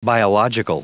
Prononciation du mot biological en anglais (fichier audio)